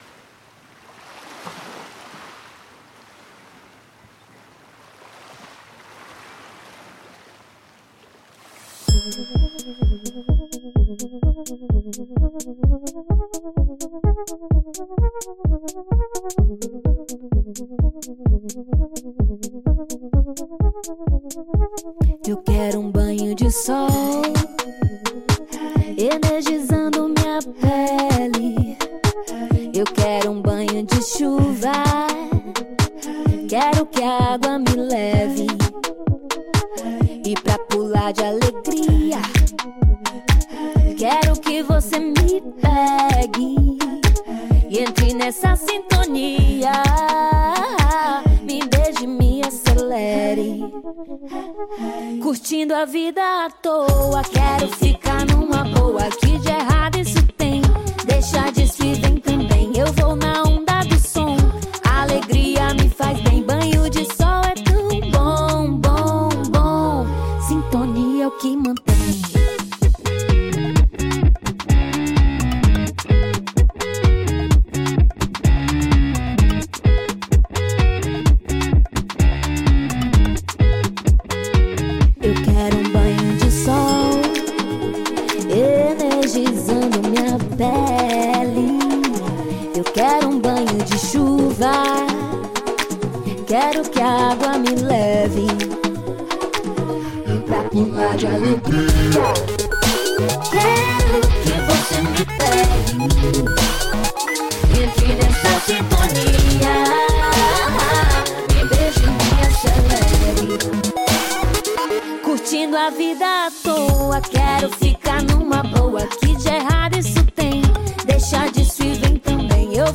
mesclando a música pop, eletrônica com ritmos da Bahia